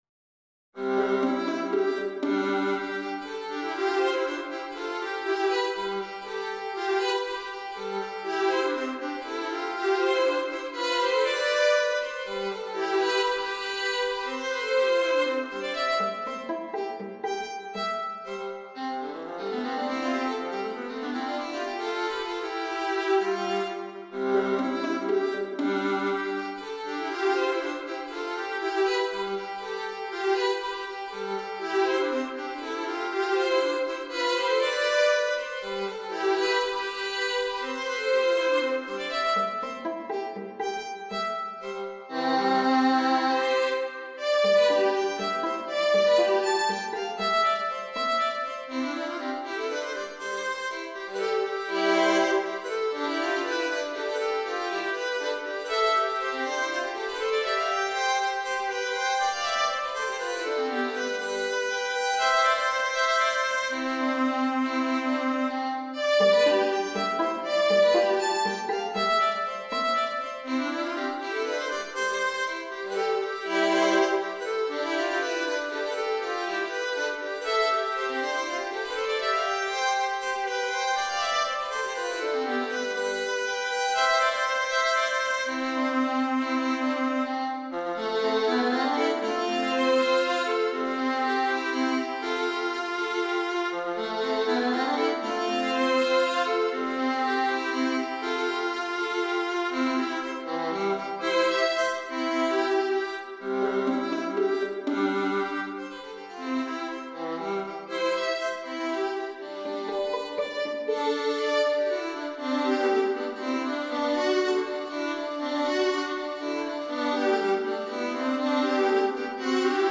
The second offered in their digital collection is "A bißl hin und a bißl her," In three-quarter time, its lilt could suggest